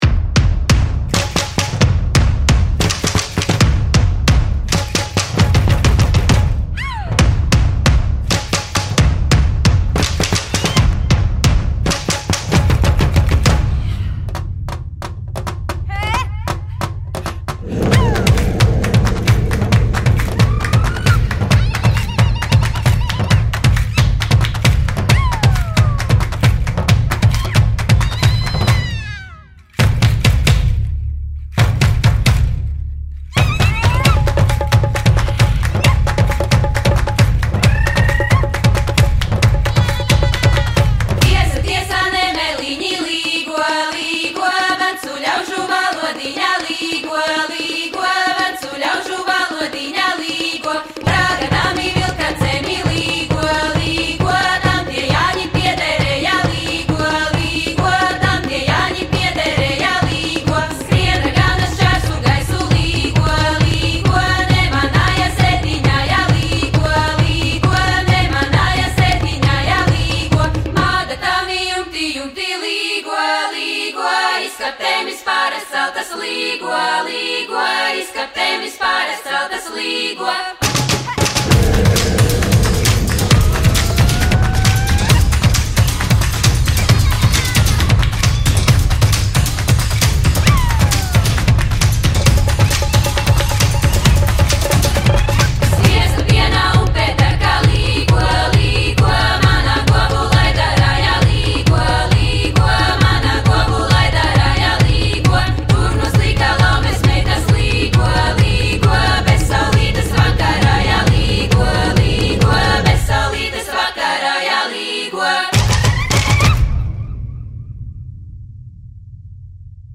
BPM134
Audio QualityCut From Video